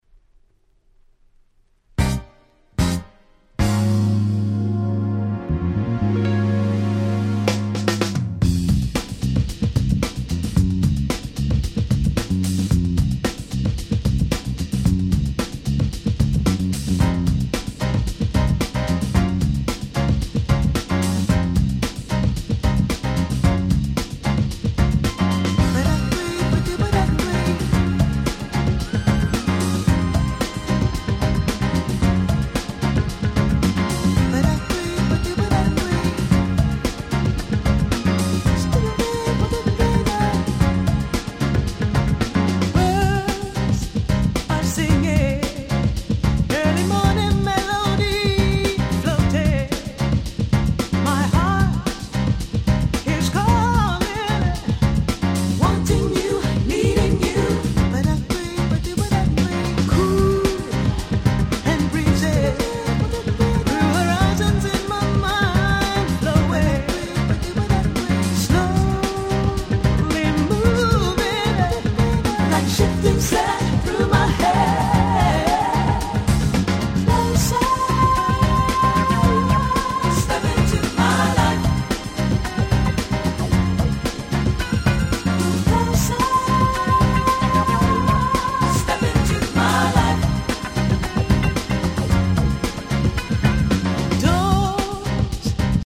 JazzyでFunkyなInst Acid Jazzナンバーでこれまた爽快の極み！！